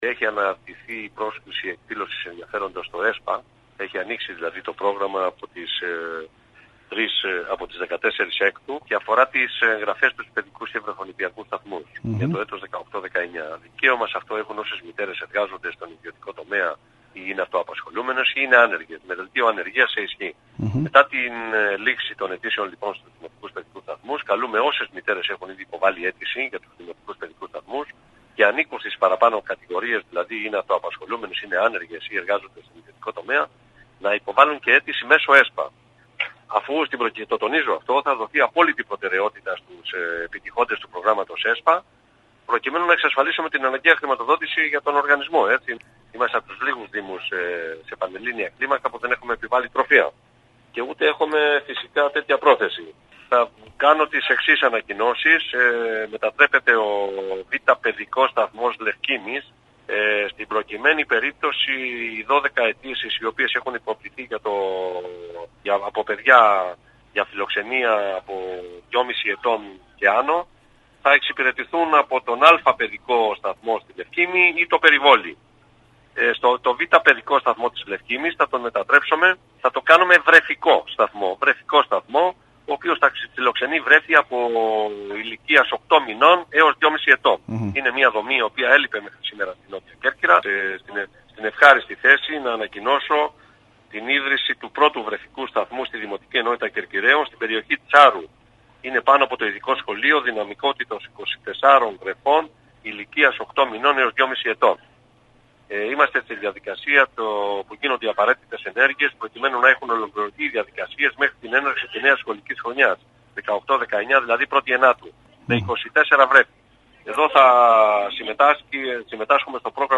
Ακούστε τις δηλώσεις του κ. Σωτ. Καζιάνη στον σύνδεσμο που ακολουθεί: